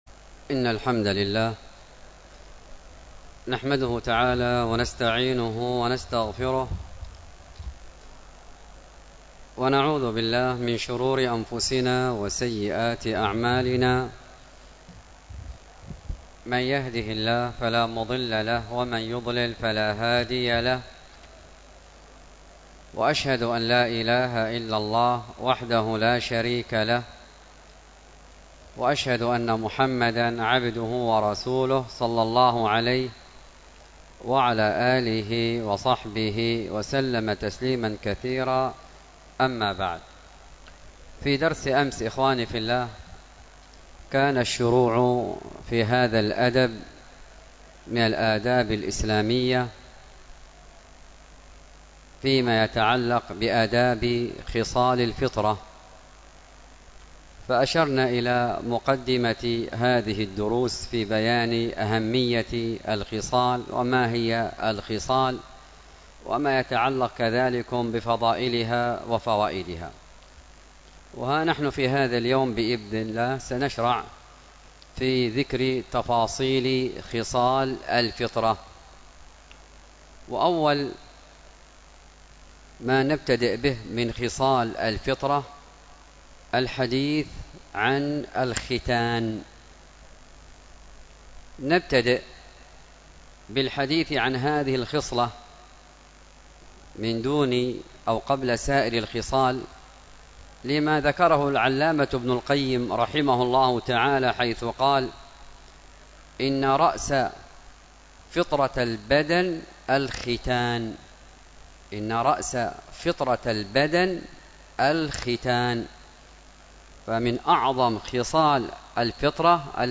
الدرس في آداب خصال الفطرة 4، تقليم الأظافر : حكمه ومدته وترتيبه والعديد من مسائله .